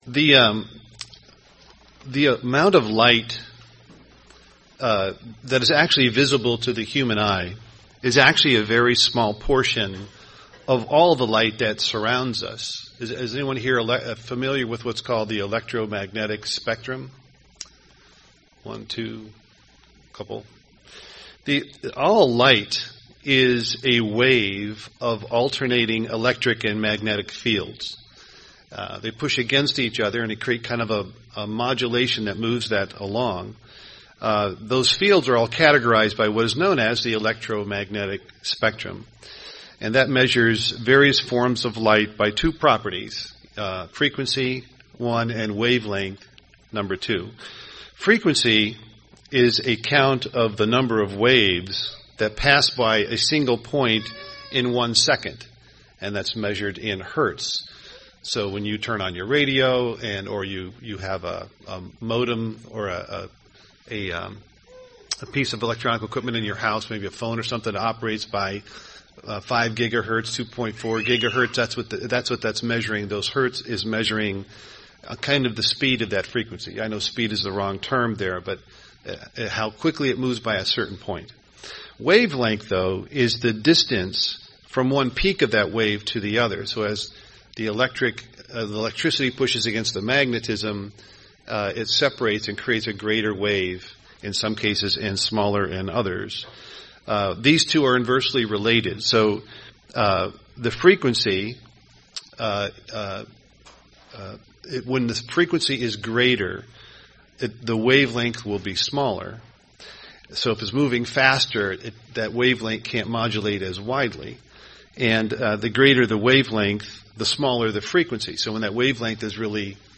UCG Sermon Atonement Satan the Devil Studying the bible?